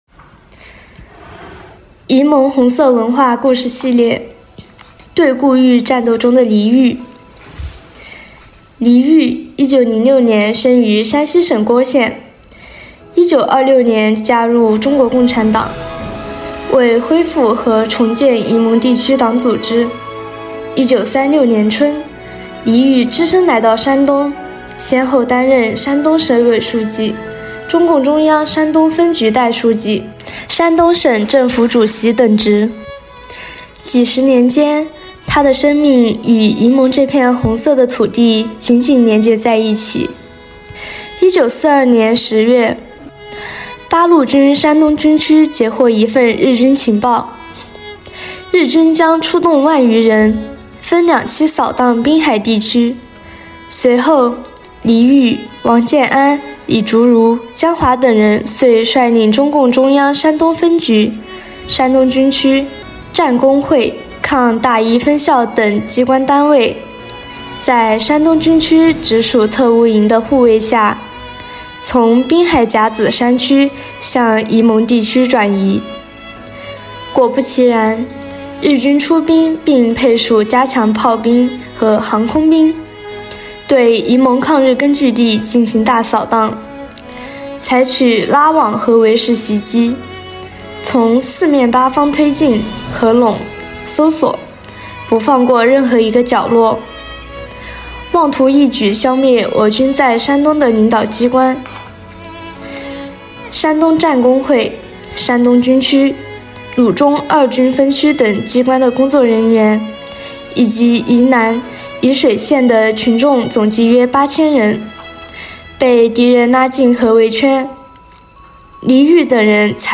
朗读音频